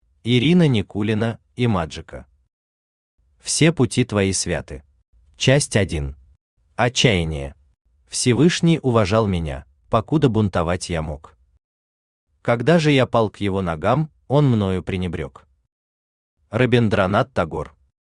Аудиокнига Все пути твои святы | Библиотека аудиокниг
Aудиокнига Все пути твои святы Автор Ирина Никулина Имаджика Читает аудиокнигу Авточтец ЛитРес.